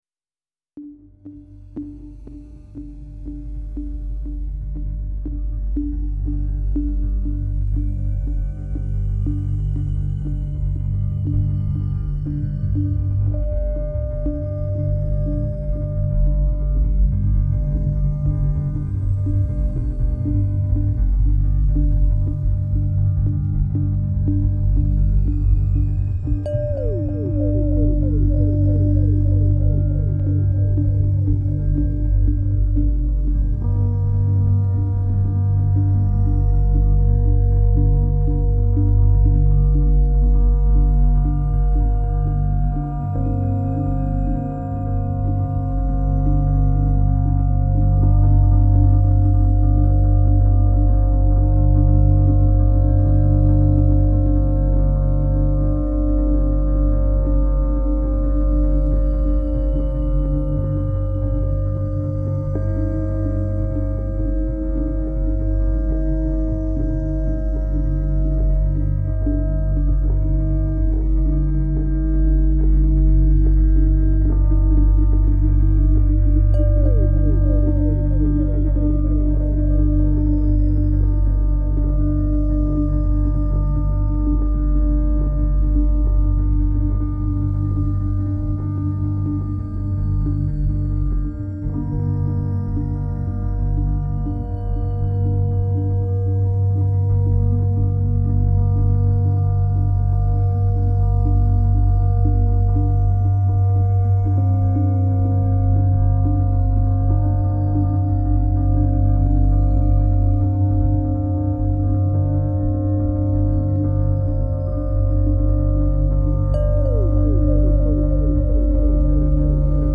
Genre : Film Soundtracks